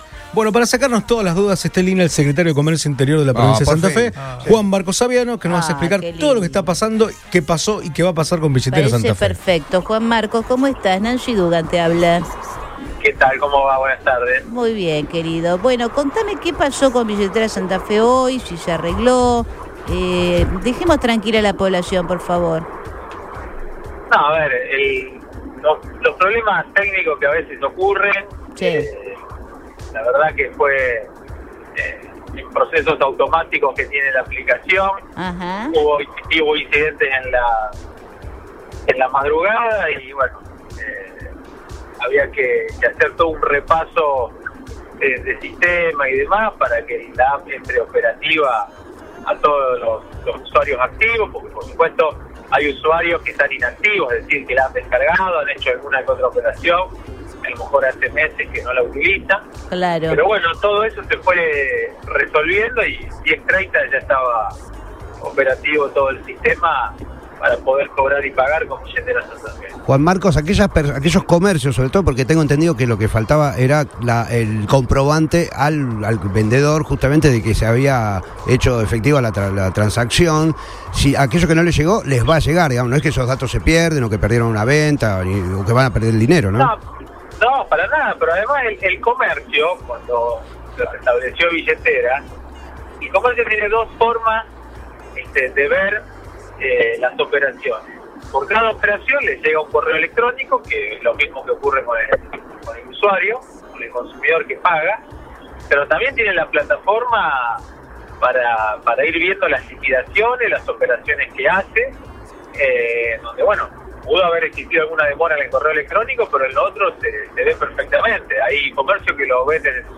Juan Marcos Aviano, responsable del sector, conversó con Todo Pasa por Radio Boing y destacó: “Los problemas técnicos que a veces ocurren. Fue un proceso automático de la aplicación y había que hacer todo un repaso de sistema para que esté operativa“.